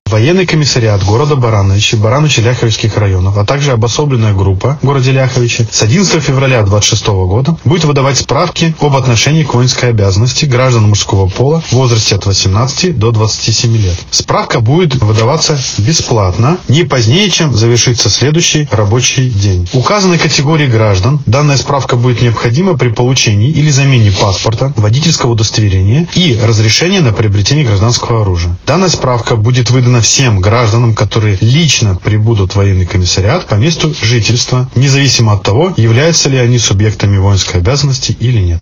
О том, как получить документ, какой категории лиц и для чего он нужен, — рассказал военный комиссар города Барановичи, Барановичского и Ляховичского районов полковник Алексей Синчук.